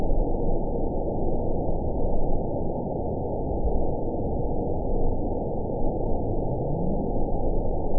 event 920188 date 03/04/24 time 12:54:06 GMT (1 year, 3 months ago) score 9.53 location TSS-AB07 detected by nrw target species NRW annotations +NRW Spectrogram: Frequency (kHz) vs. Time (s) audio not available .wav